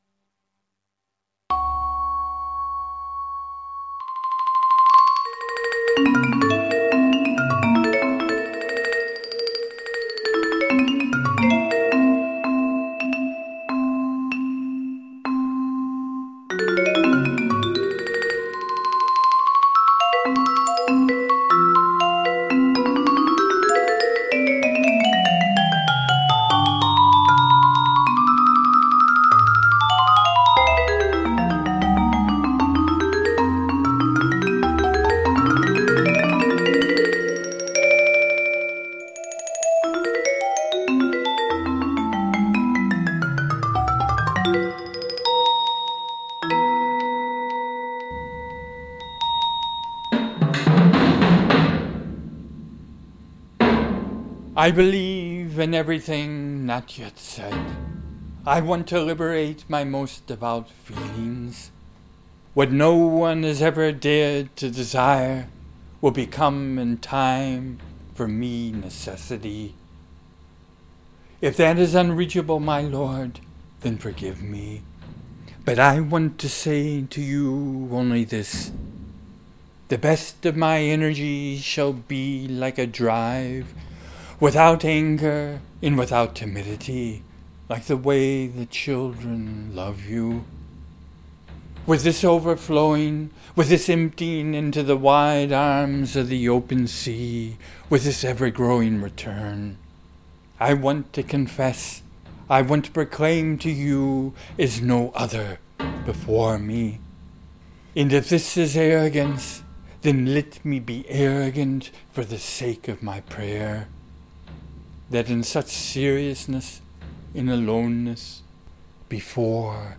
music is from my TIMEINE, for vibes & marimba,
and MINTAKA, for 7 drums